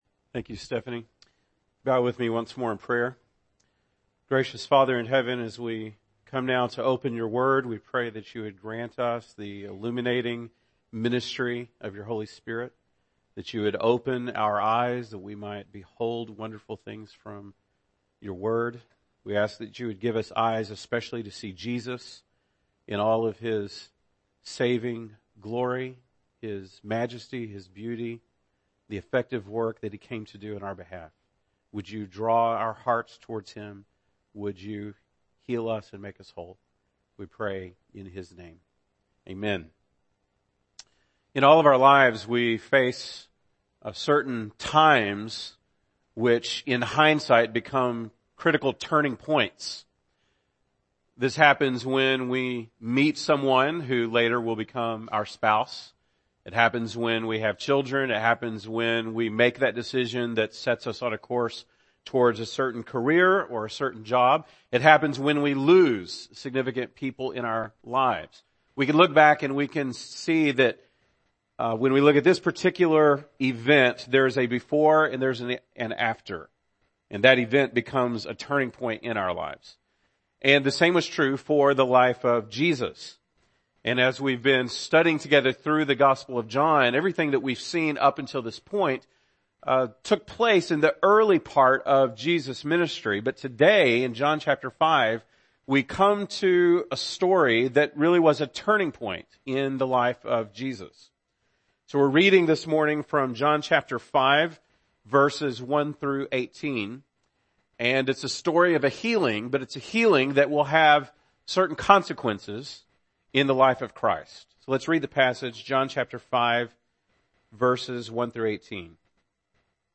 March 26, 2017 (Sunday Morning)